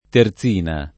[ ter Z& na ]